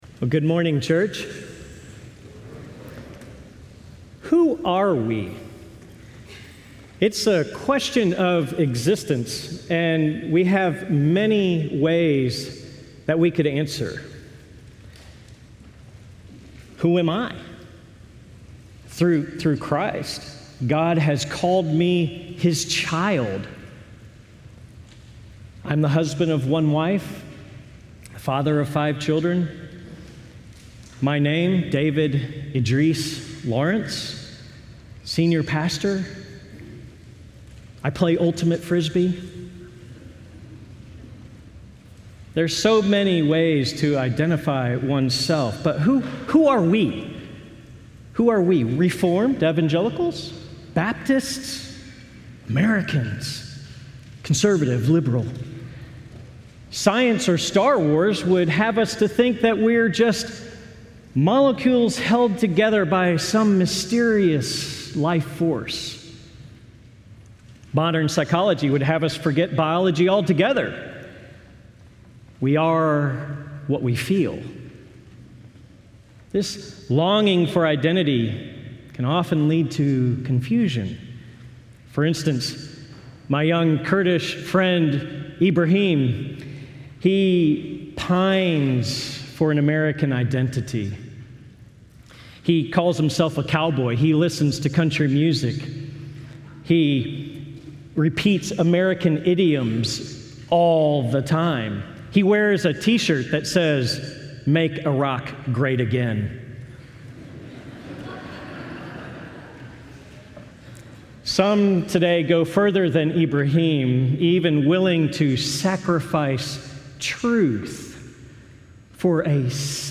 Livestream Video & Sermon Audio